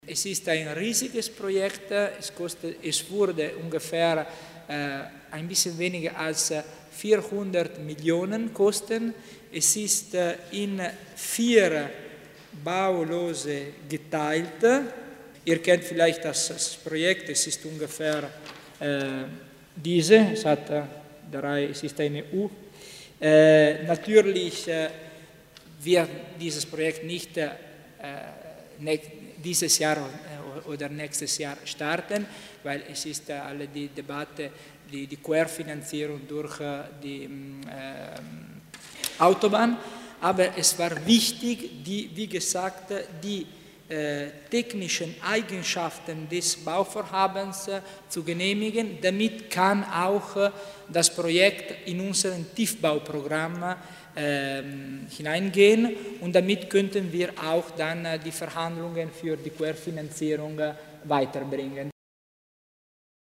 Landeshauptmannstellvertreter Tommasini erklärt das Projekt zur Umfahrung von Bozen